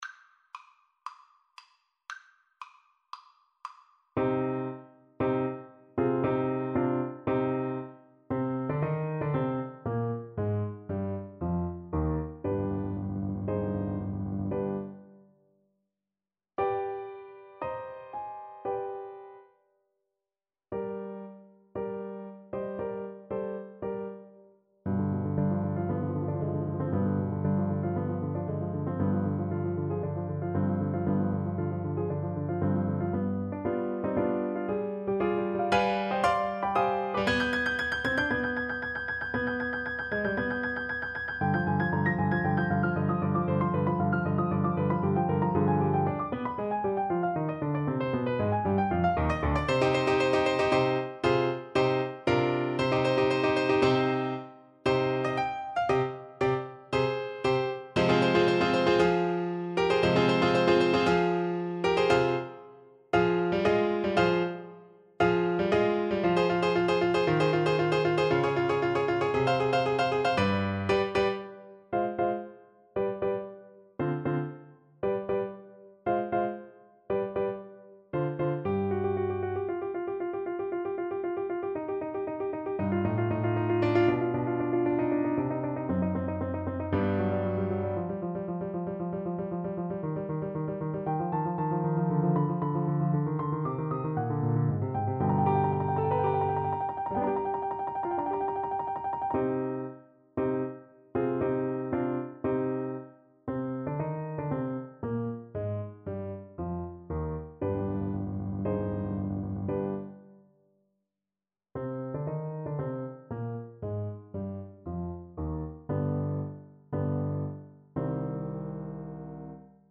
Play (or use space bar on your keyboard) Pause Music Playalong - Piano Accompaniment Playalong Band Accompaniment not yet available transpose reset tempo print settings full screen
C minor (Sounding Pitch) A minor (Alto Saxophone in Eb) (View more C minor Music for Saxophone )
Allegro =116 (View more music marked Allegro)
Classical (View more Classical Saxophone Music)